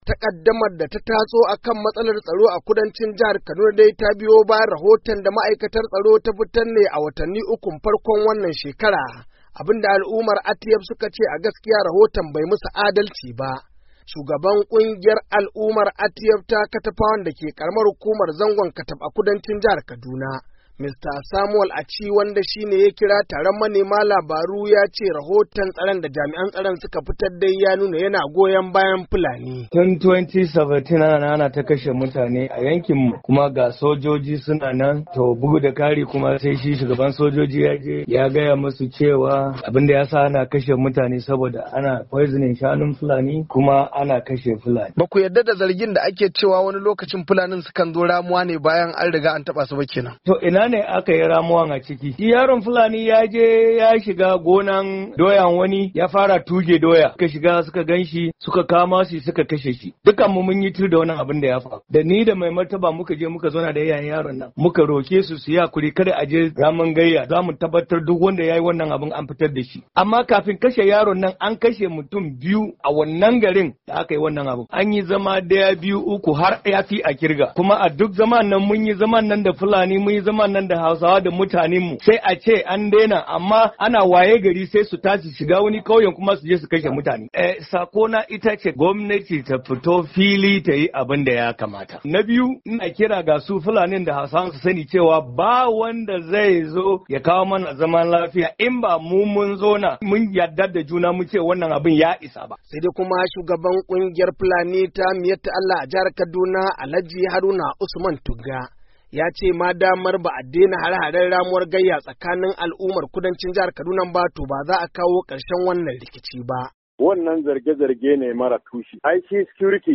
Rahotu Na Musamman Akan Hare-Haren Kudancin Kaduna.mp3